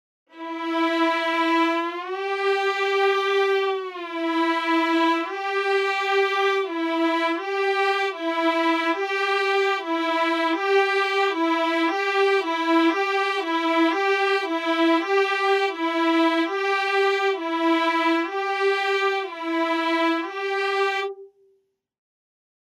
Ensemble legato
Instead of a fluid legato transitions I have a noticeable volume gap between notes as if all the virtual players change the bow direction, even if I want slur legato.
The same goes for portamento, there is a little volume drop in the transition and all the virtual players play the same pitch at once. Here is a quick example going from low velocity to high and back. The gap is the most noticeable in the mid velocities
In your example even the usually not synchronized vibratos of the player seem to get synchronized uring the legato phase.